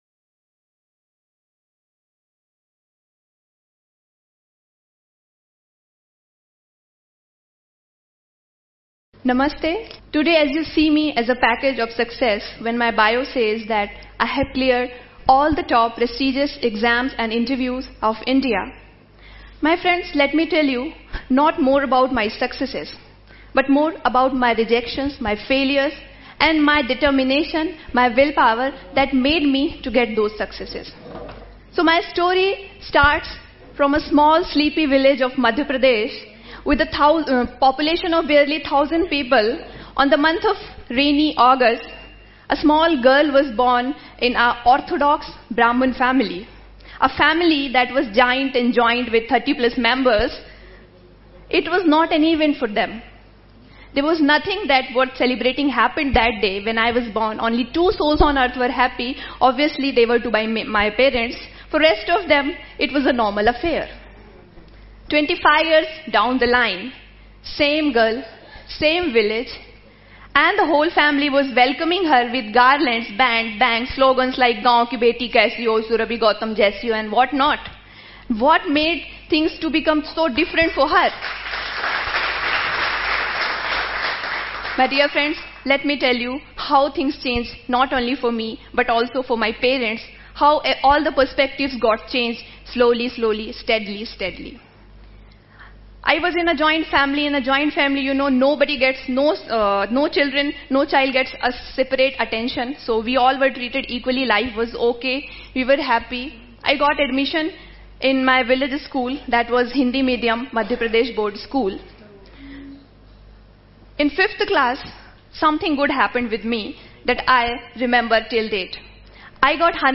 Here is the full transcript of IAS officer Surabhi Gautam’s TEDx Talk: How “SHE” Became an IAS Officer at TEDxRGPV conference.